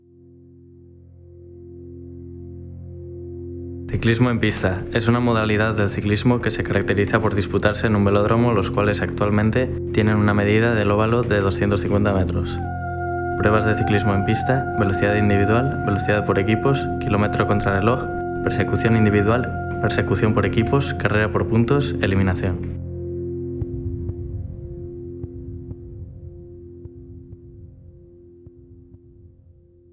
Se podrá utilizar una grabación de voz cualquiera de las realizadas en clase.
Deberá contener una banda sonora de fondo a modo de anuncio.